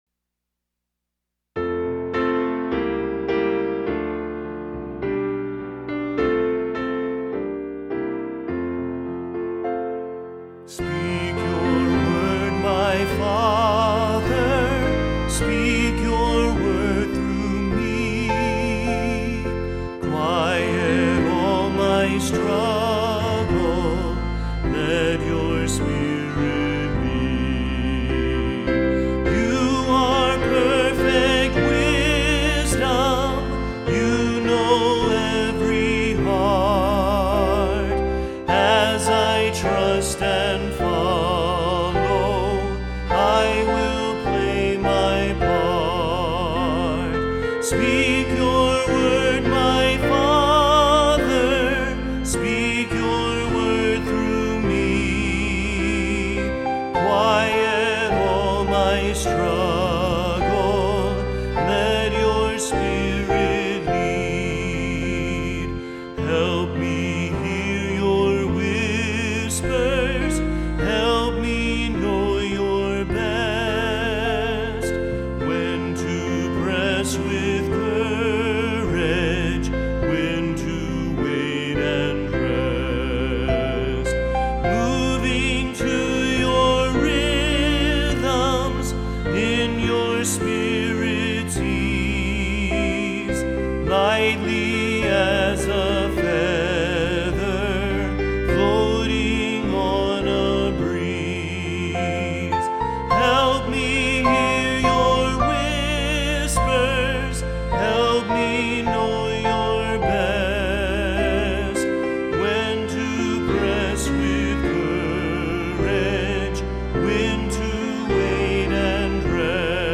Hymn: Speak Your Word, My Father